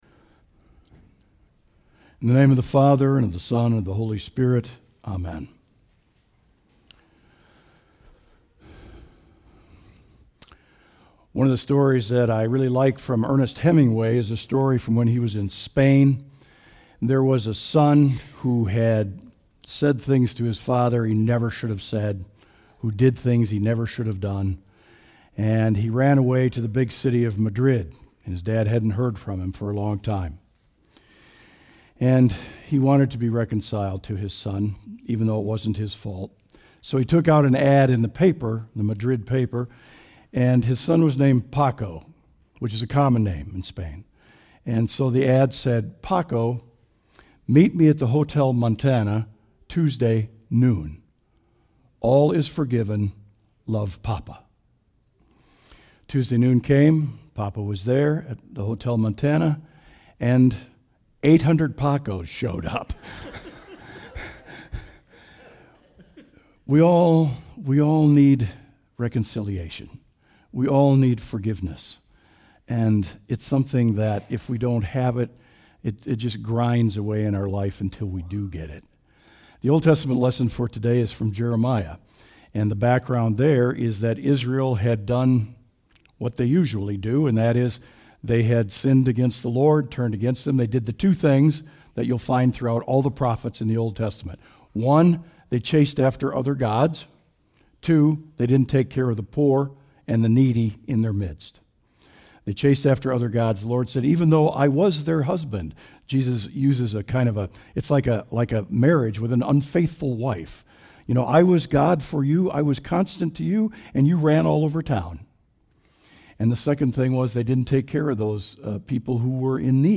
Audio Sermon “Let It Go”